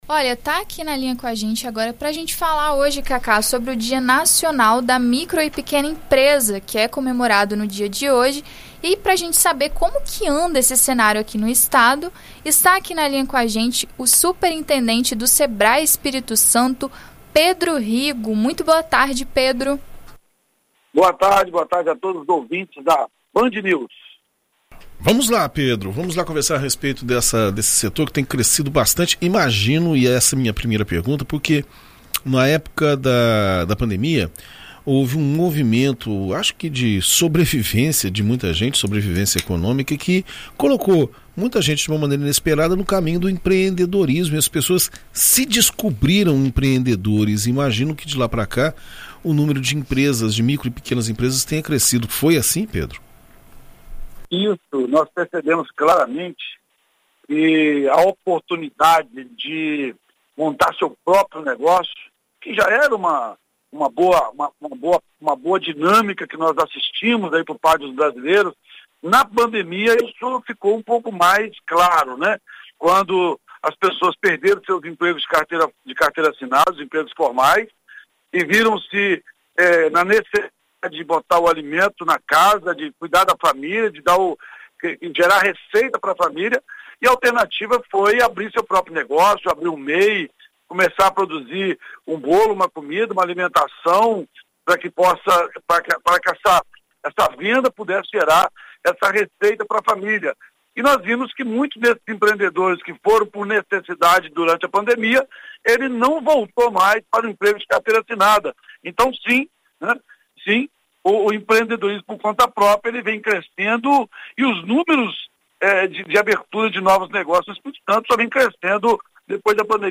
Em entrevista à BandNews FM ES nesta quinta-feira (05)